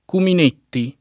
[ kumin % tti ]